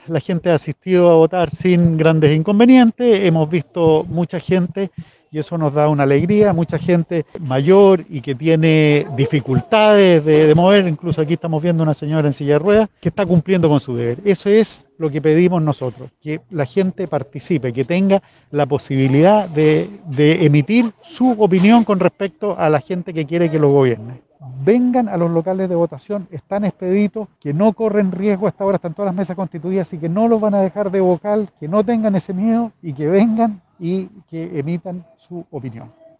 Nuevamente se ha visto un flujo mayor de adultos mayores que han llegado a los colegios, escuelas y liceos de Osorno. Es por eso que el Intendente Regional, Carlos Geisse, comentó a las afueras del Liceo Eleuterio Ramírez la situación actual de este segundo día de votaciones, que se ha desarrollado sin inconvenientes, invitando al electorado a sufragar.